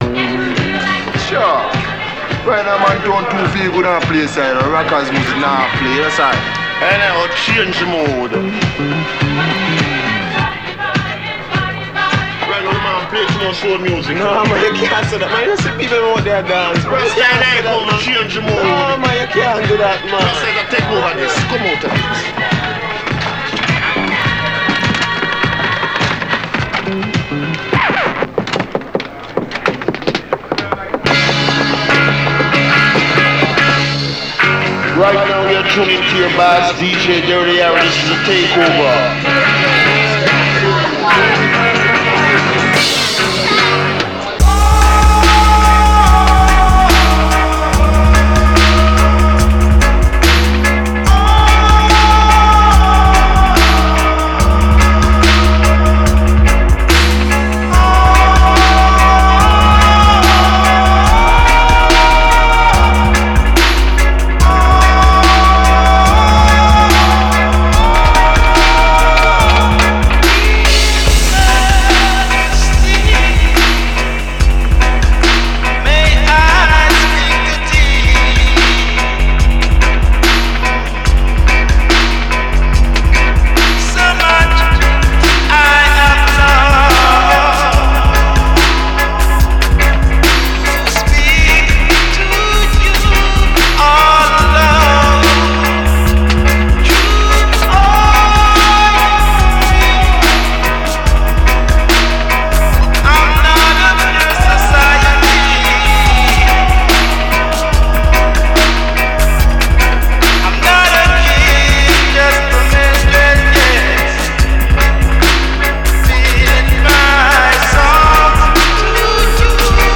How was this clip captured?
Mixed in Edinburgh